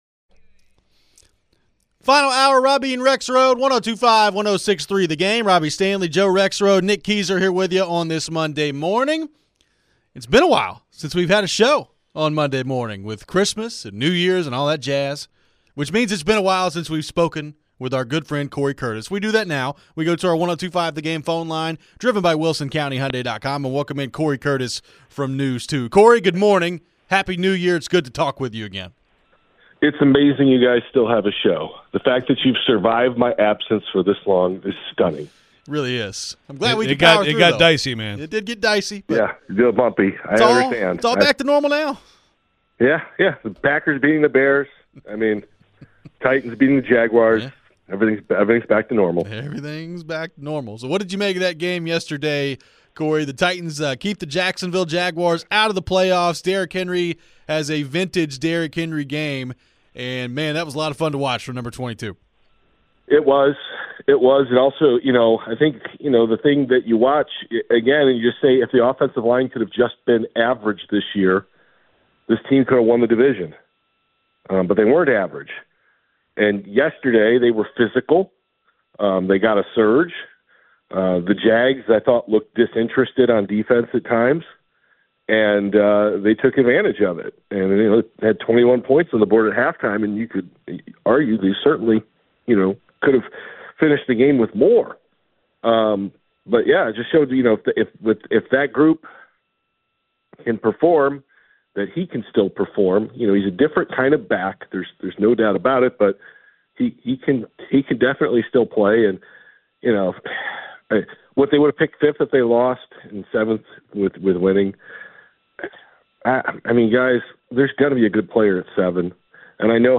talk the Titans and take phone calls